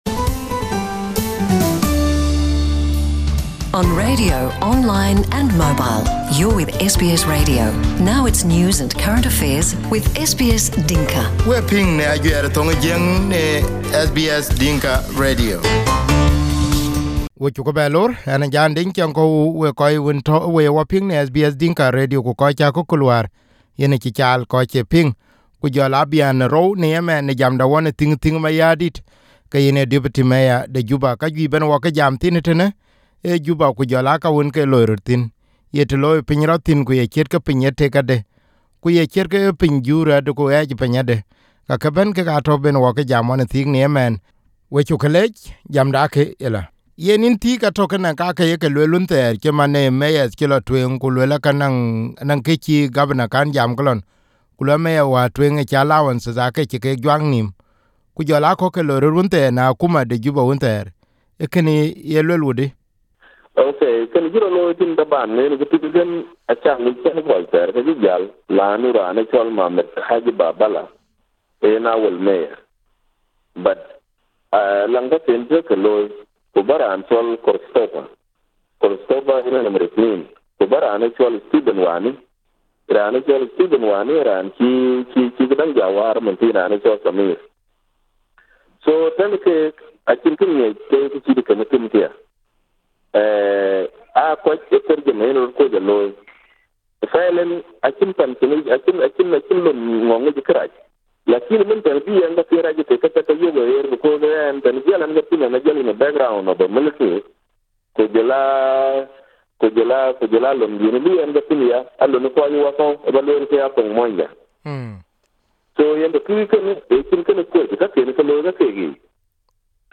Deputy Mayor Thiik Thiik Mayardit in the second part of our interview talks about the need to change Juba city for better.